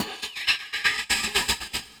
For this tutorial we will use the file bgsound.aif, this sound file is carefully edited in sound forge to give a seamless audio loop.
BGSound.aif